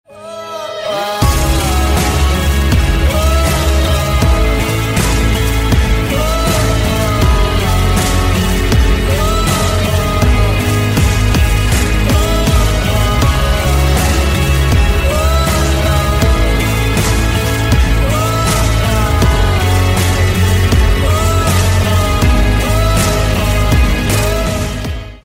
Рингтоны Ремиксы » # Рэп Хип-Хоп Рингтоны